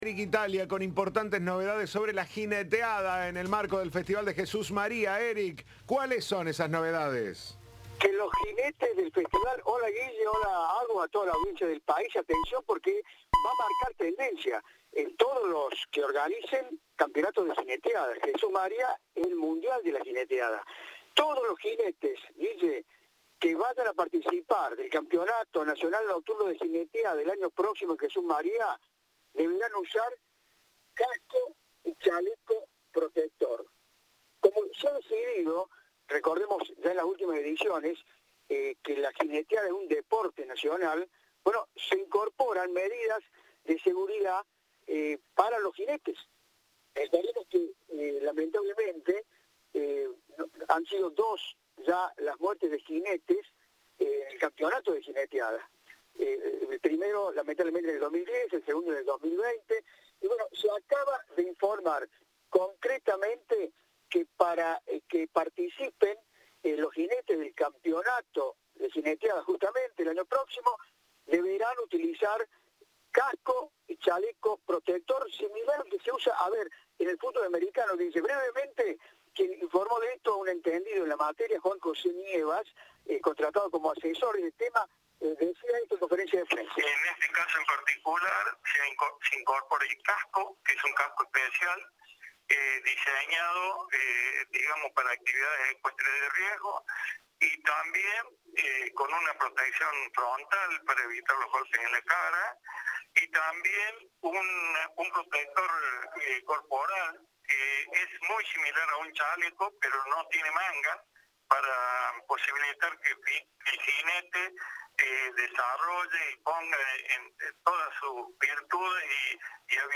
Entrevista de Turno Noche.